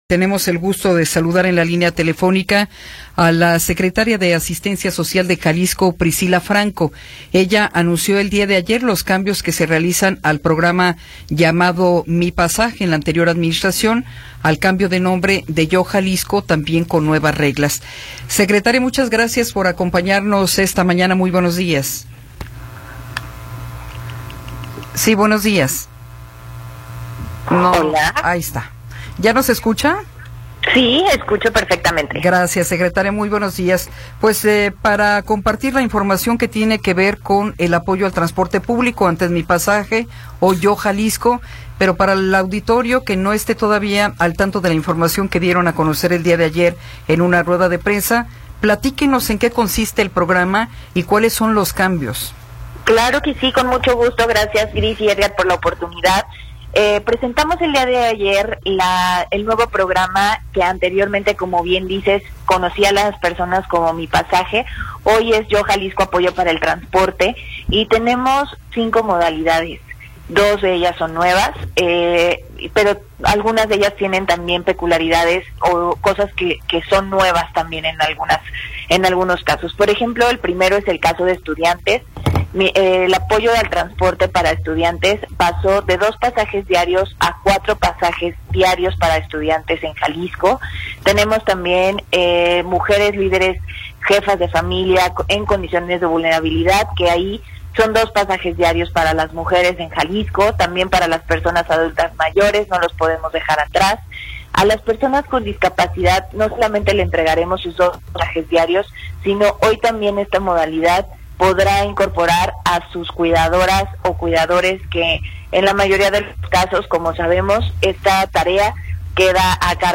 Entrevista con Priscilla Franco Barba